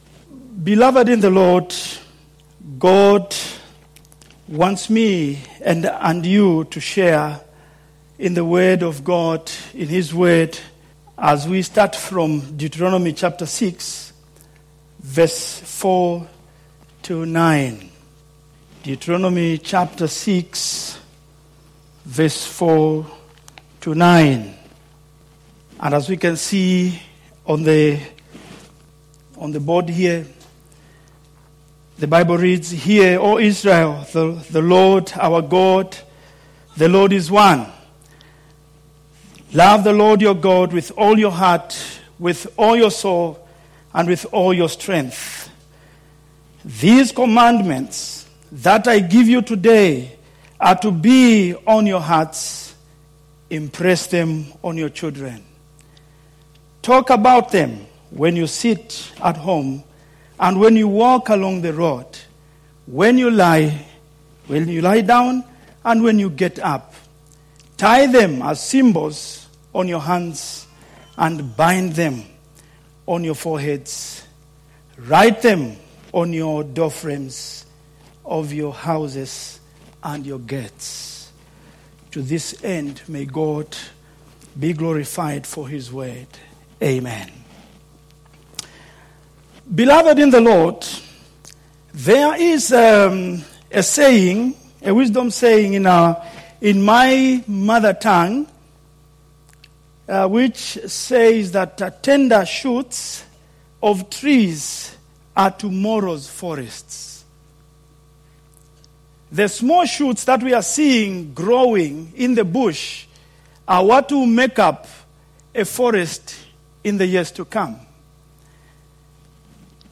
Vineyard Groningen Sermons